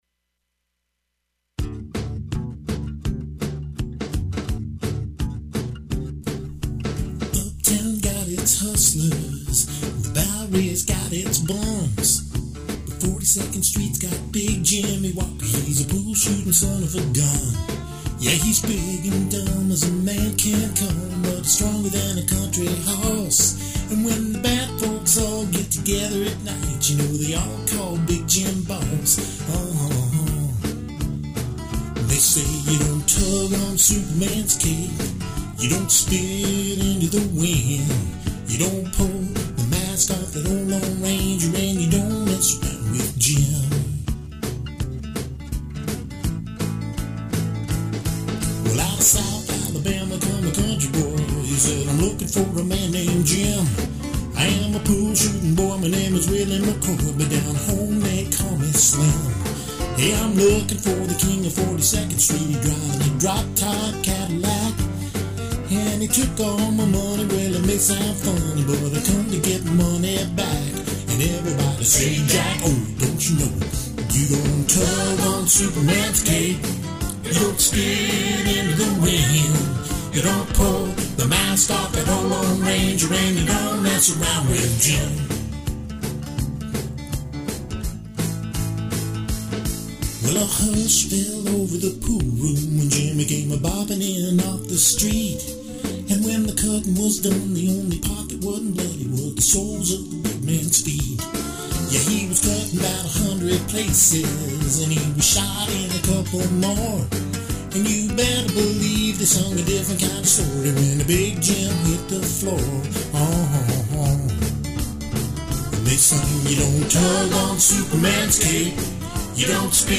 Drum machine